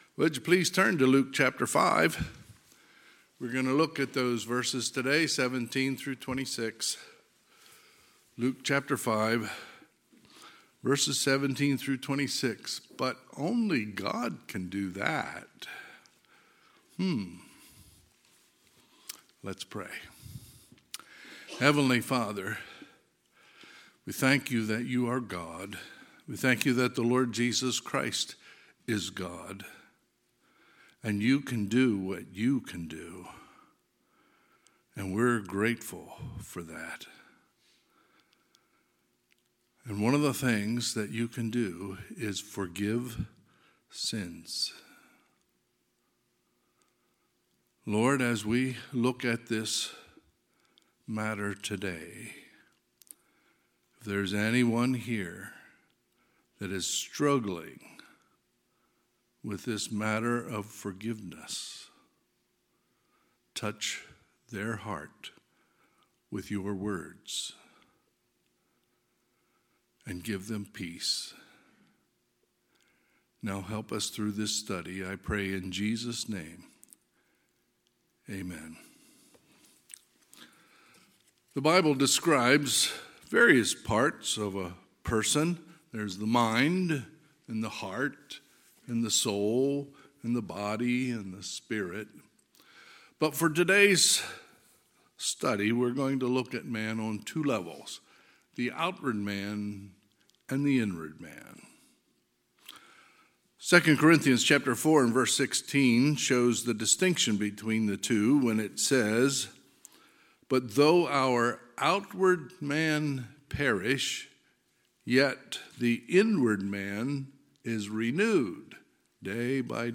Sunday, February 26, 2023 – Sunday AM